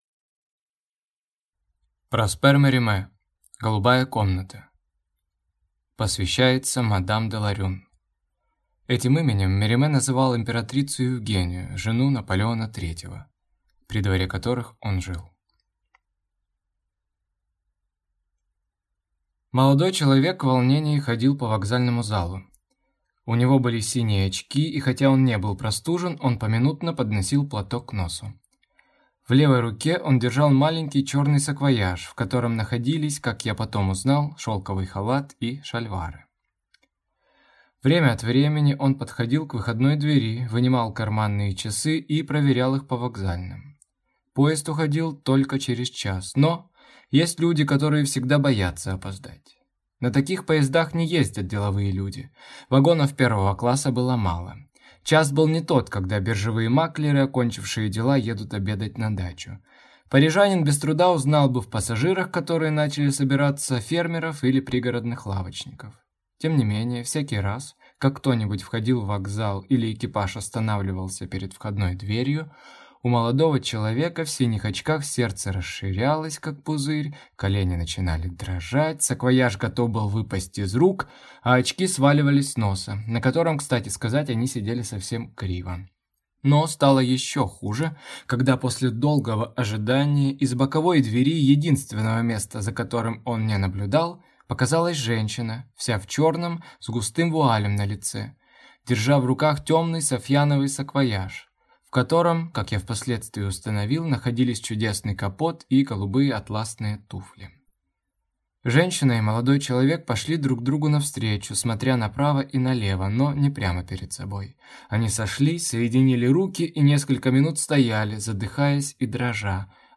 Аудиокнига Голубая комната | Библиотека аудиокниг